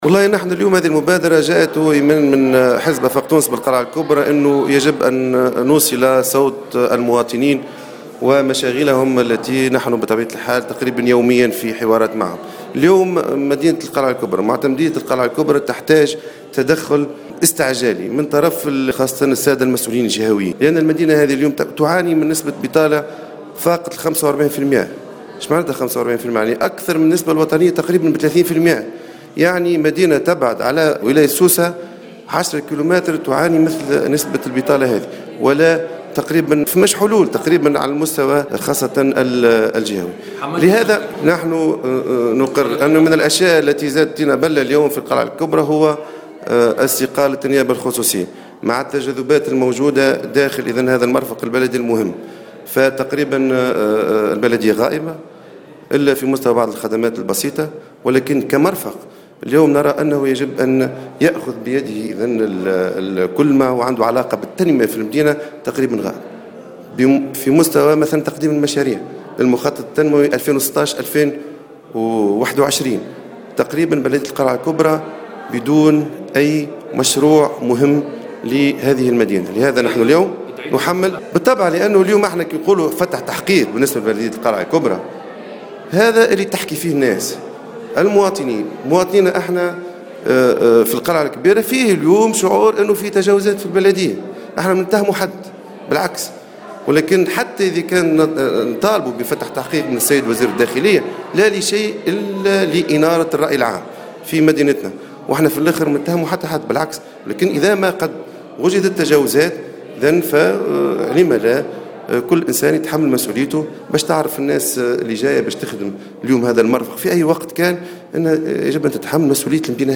عقد اليوم السبت المكتب المحلي لحزب أفاق تونس بالقلعة الكبرى ندوة صحفية بمقر الحزب لتسليط الضوء على مشاغل المواطنين بعد تردي الوضع التنموي بالجهة واستقالة النيابة الخصوصية .